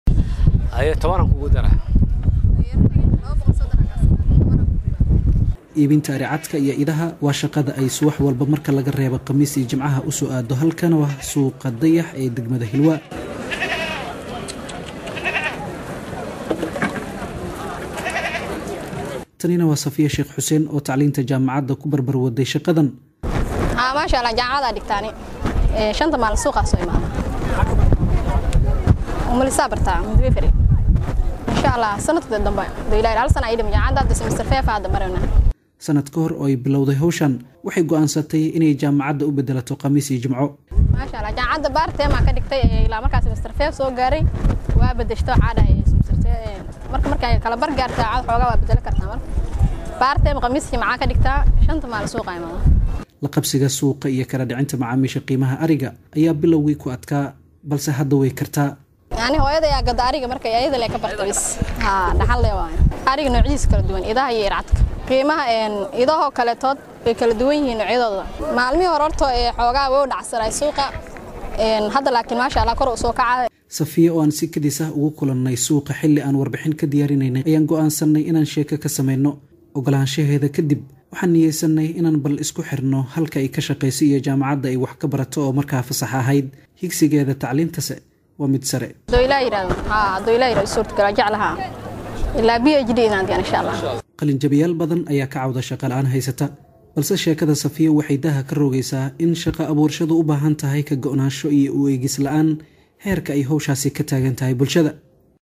Warbixintan soo socota ayey ugu hadleysaa safarka nolosheeda